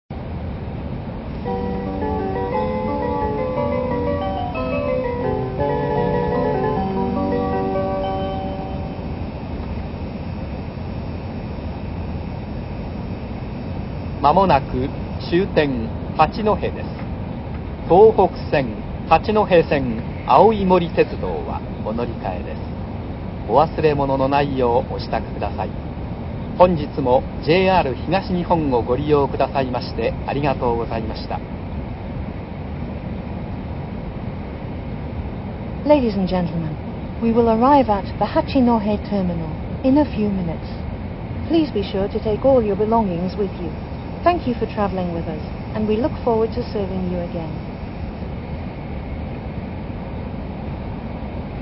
ＪＲ東日本 車内放送
八戸到着前   東北・山形・秋田新幹線チャイム
hayate9go-hachinohe.mp3